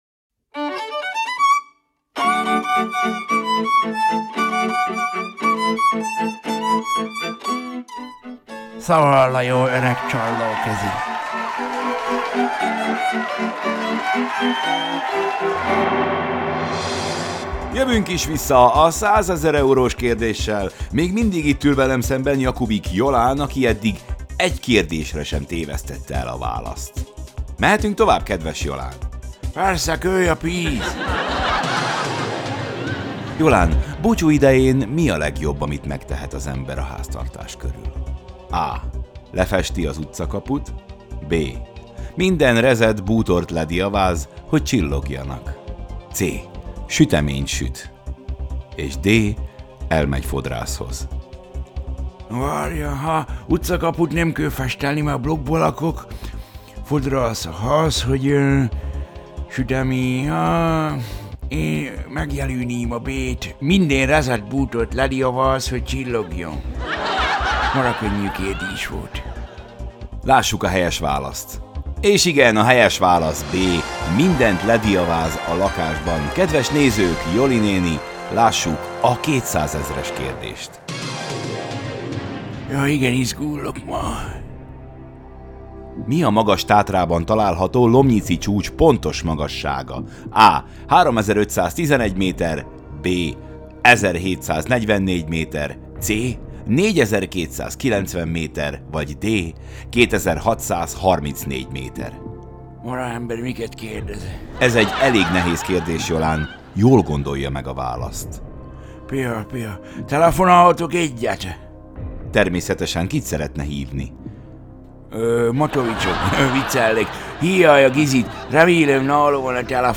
Szaval a jó öreg csallóközi
Zene: